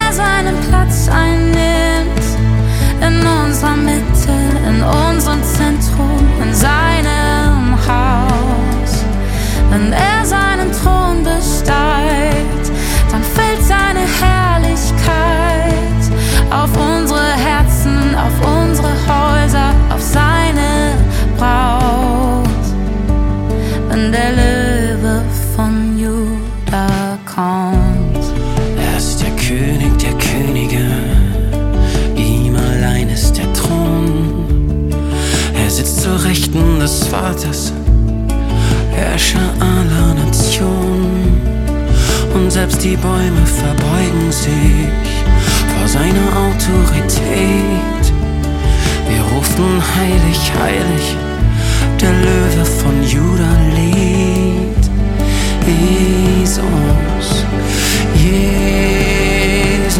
Lobpreis